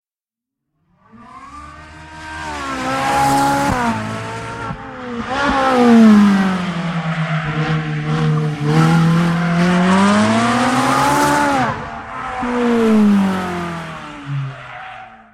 3D Tones